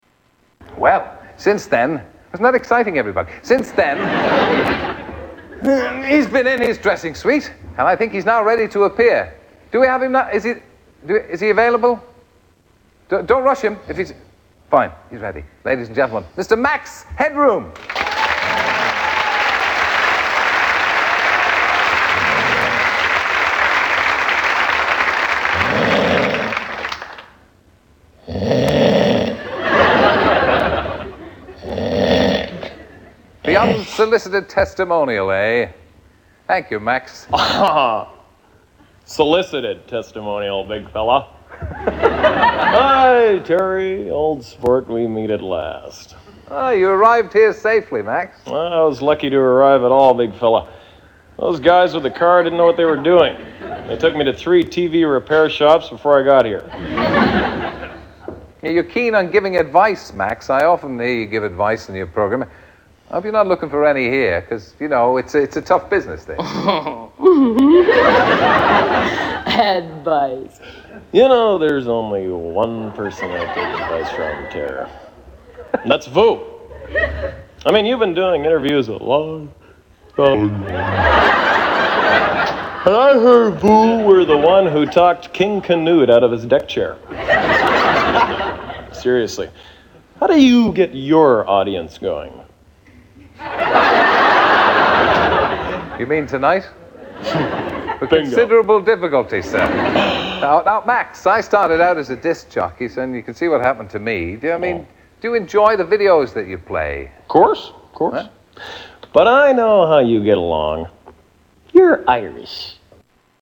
Terry Wogan interviews Max Headroom
Category: Television   Right: Personal